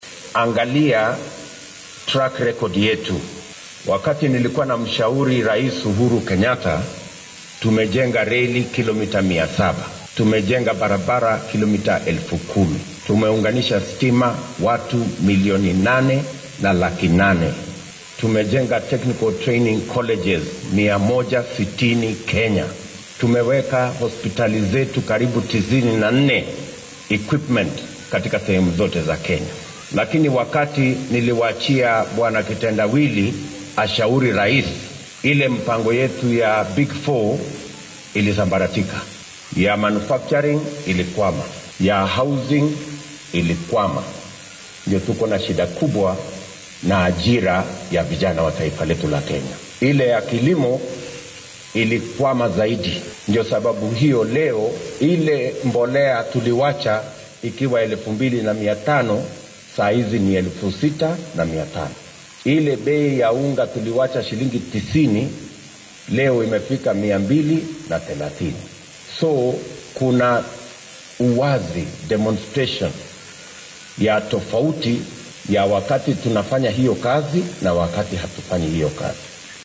Ruto oo isku soo baxyo siyaasadeed ku qabanaya ismaamulka Meru ayaa hoosta ka xarriiqay in heshiiskii 2018-kii dhex maray ra’iisul wasaarihii hore ee dalka Raila Odinga iyo madaxweyne Uhuru Kenyatta uu weeciyay qorshihii horumarineed ee dowladda.